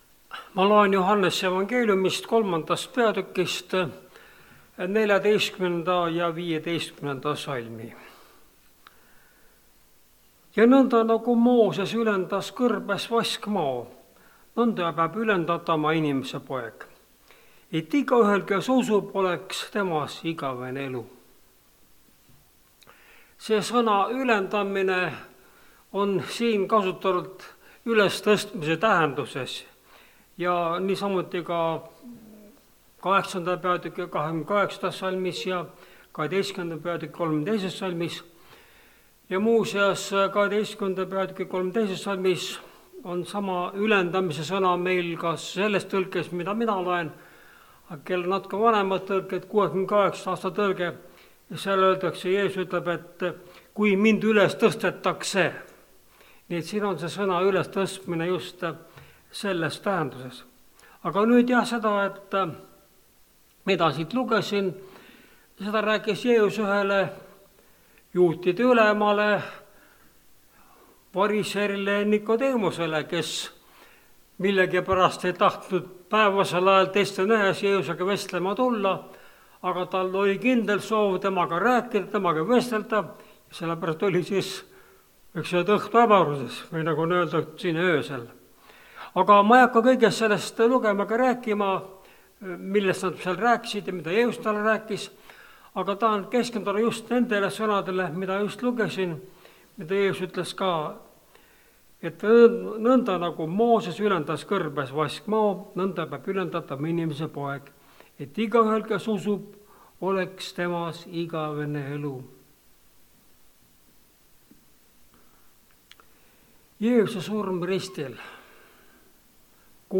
Vaskmadu ja Jeesus (Rakveres)
Jutlused